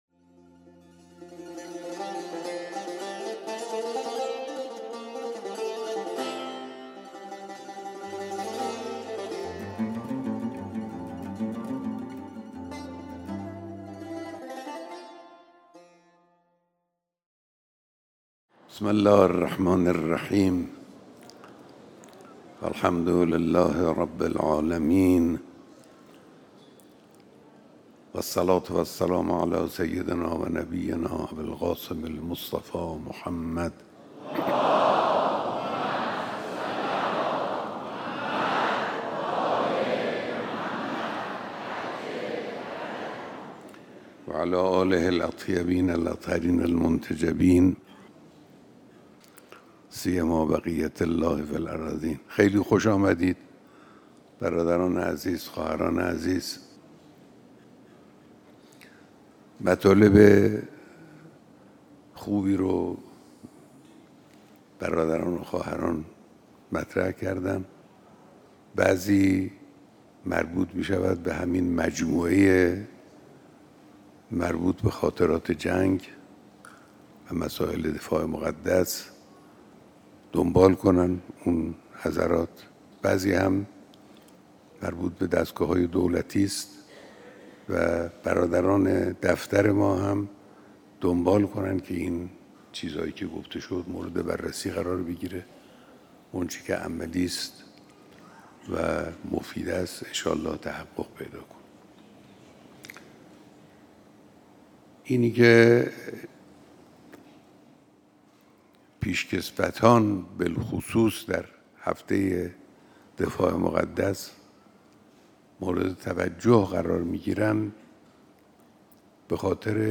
بیانات در دیدار پیشکسوتان و فعالان دفاع مقدس و مقاومت